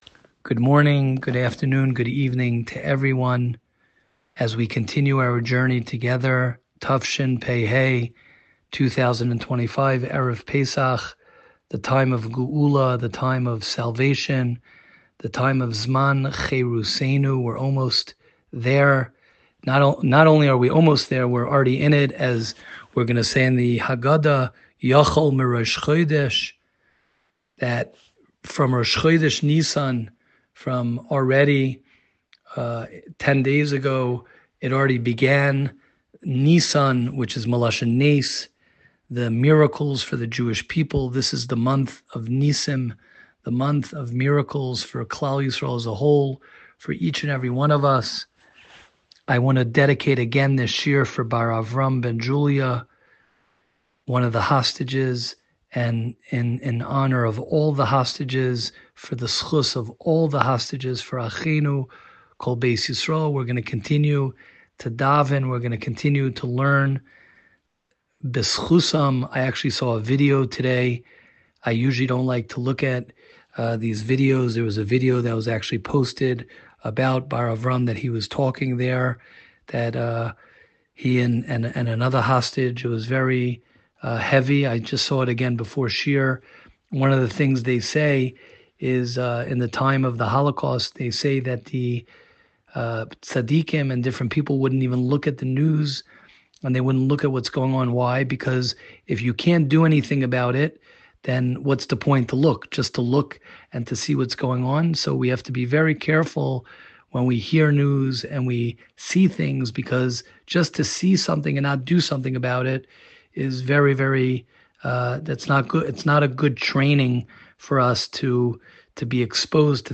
Bein Hazmanim Shiur